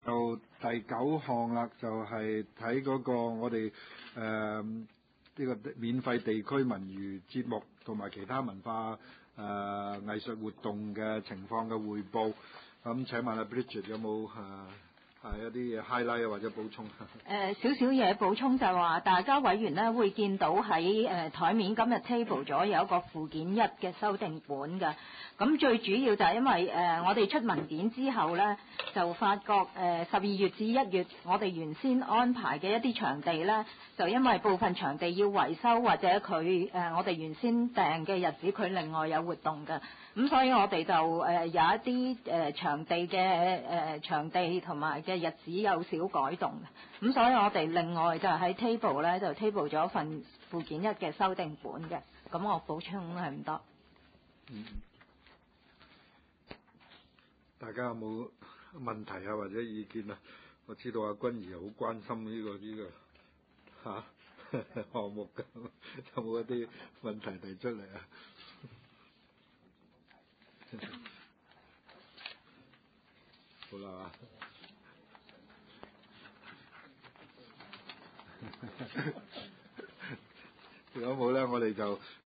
文化及康體事務委員會第五次會議
灣仔民政事務處區議會會議室